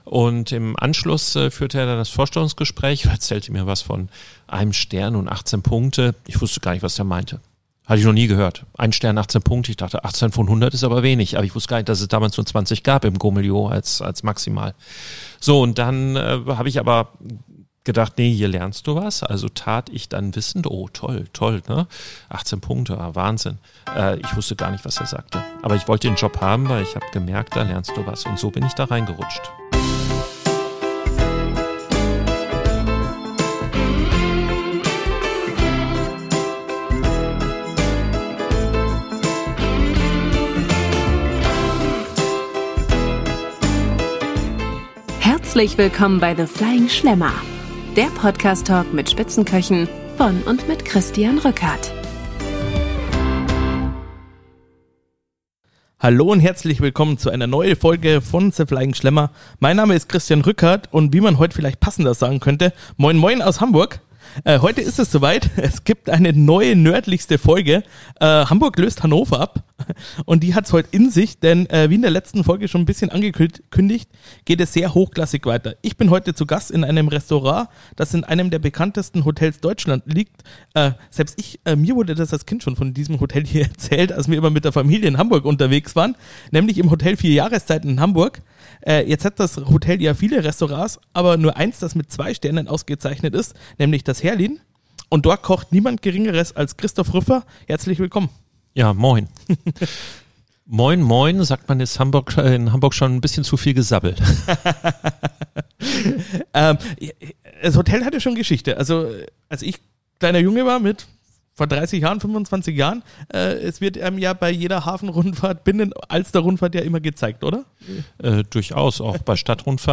- Feines ohne Filter - The Flying Schlemmer - Der Podcast Talk mit Sterneköchen